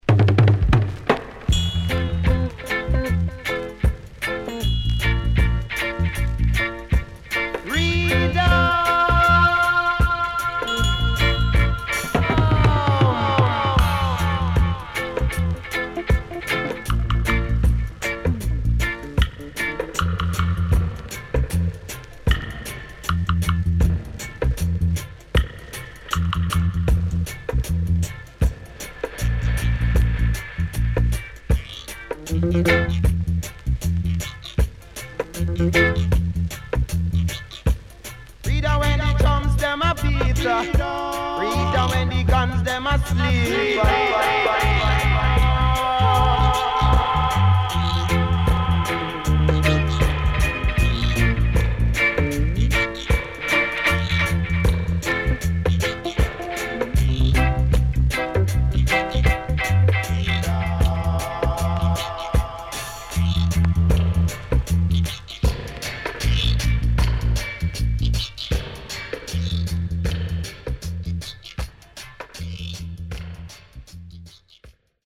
SIDE A:軽いヒスノイズ入ります。